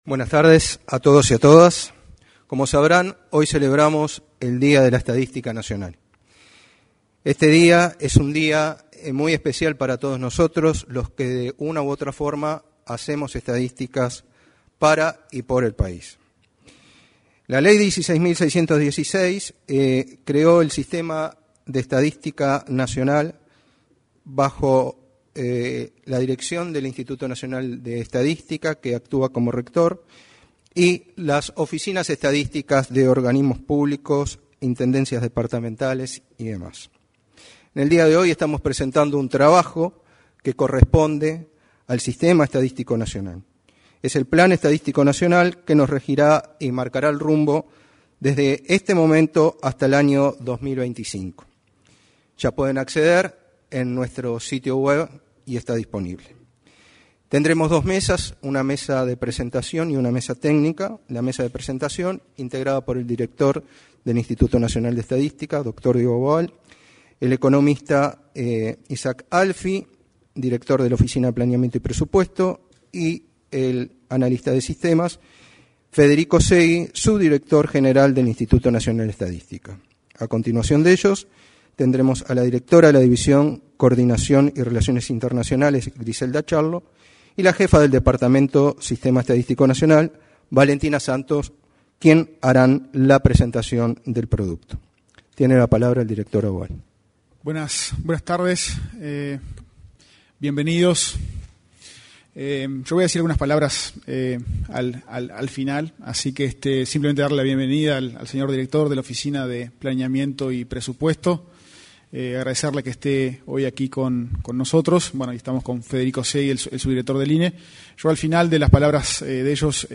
Día de la Estadística Nacional 30/09/2022 Compartir Facebook X Copiar enlace WhatsApp LinkedIn Con motivo del Día de la Estadística Nacional, el 30 de setiembre se efectuó una ceremonia en el auditorio de la Torre Ejecutiva.